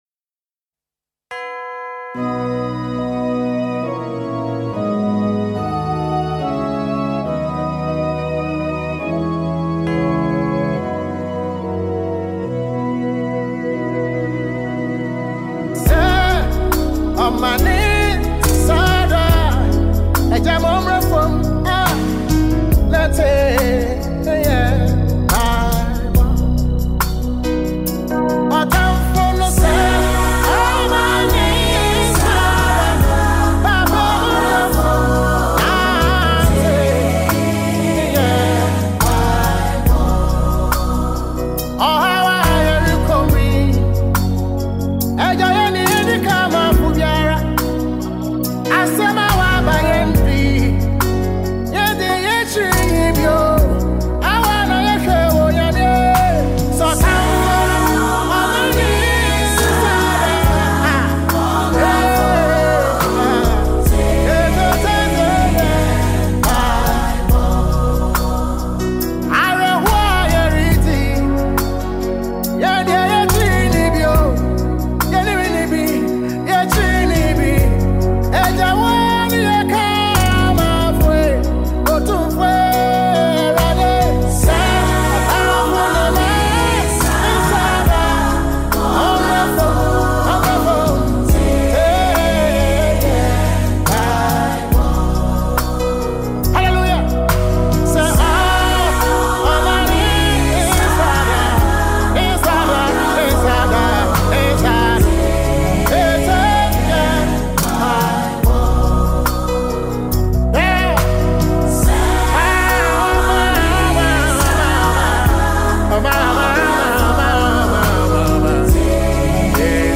2025 0 DOWNLOAD AUDIO Ghanaian gospel musician
tribute song